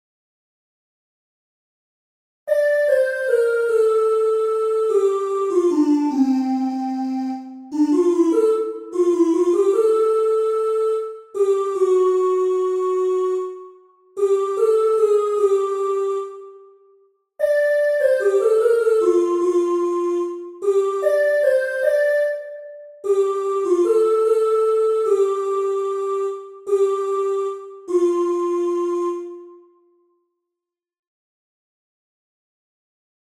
Entoación con acompañamento
Só voz:
ENTONACION9UD3-Voice.mp3